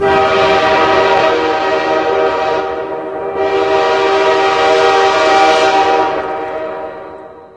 trainHorn.ogg